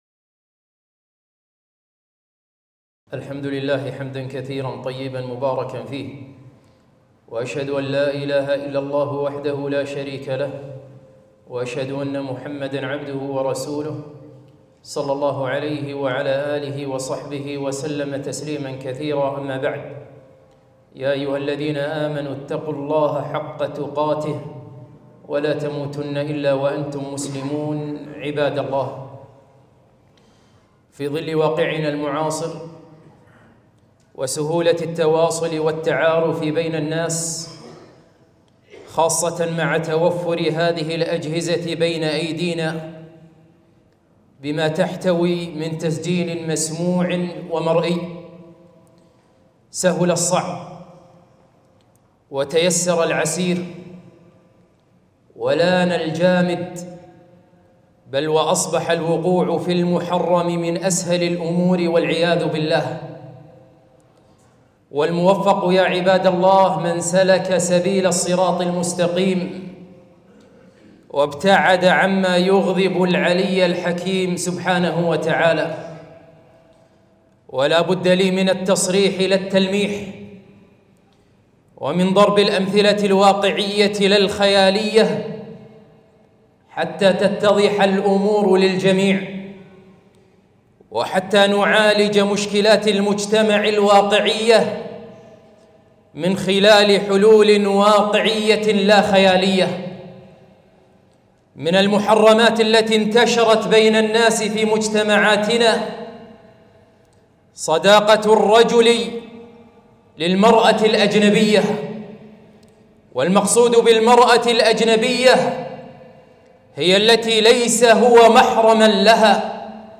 خطبة - الصداقة بين الجنسين